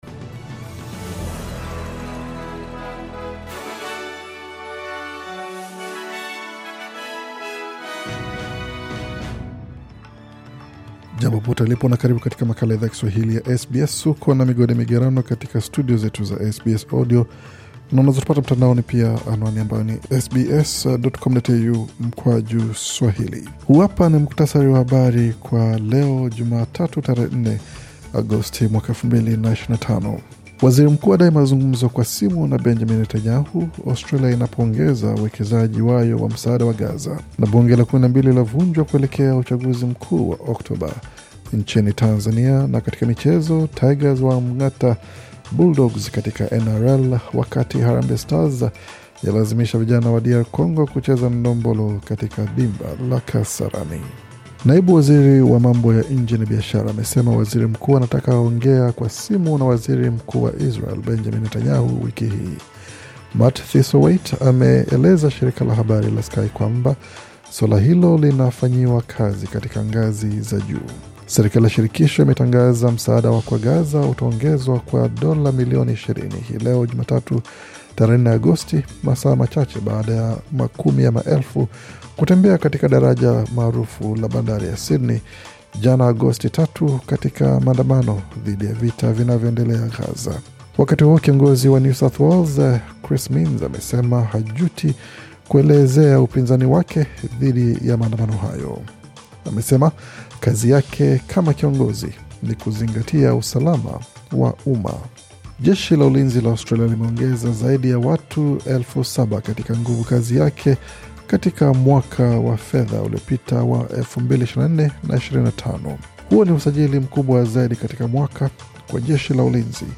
Taarifa ya Habari 4 Agosti 2025